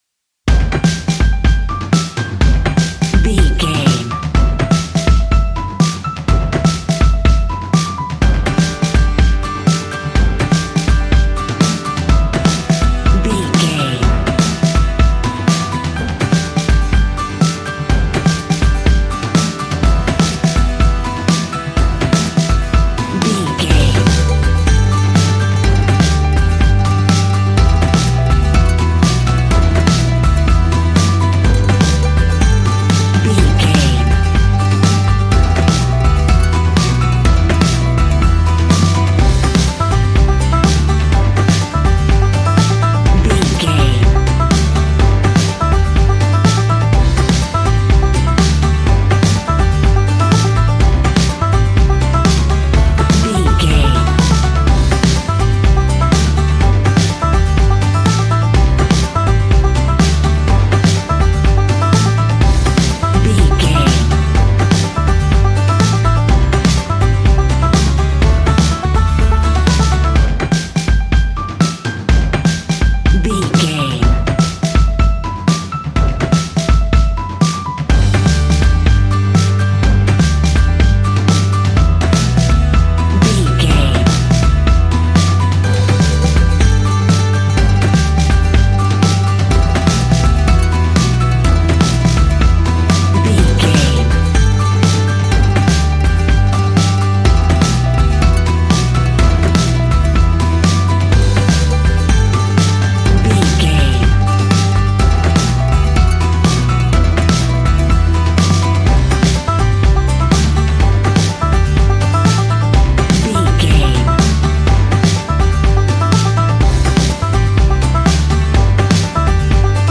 Uplifting
Ionian/Major
Fast
acoustic guitar
mandolin
ukulele
lapsteel
drums
double bass
accordion